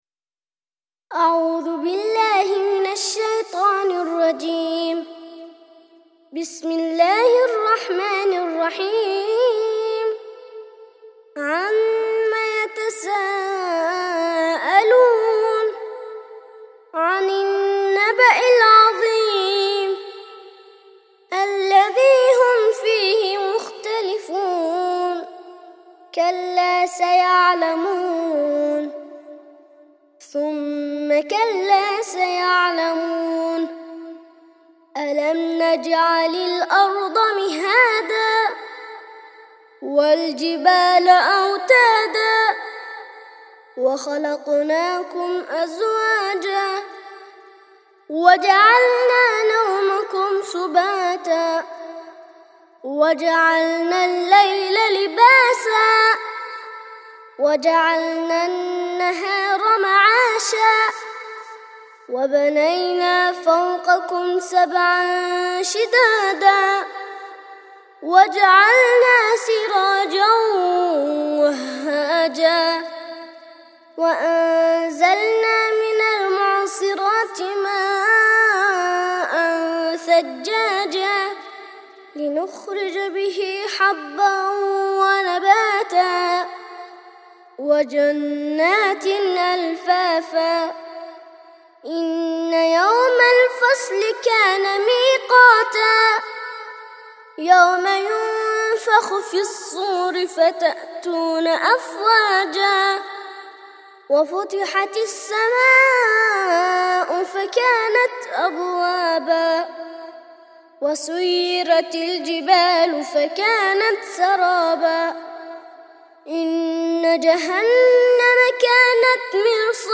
78- سورة النبأ - ترتيل سورة النبأ للأطفال لحفظ الملف في مجلد خاص اضغط بالزر الأيمن هنا ثم اختر (حفظ الهدف باسم - Save Target As) واختر المكان المناسب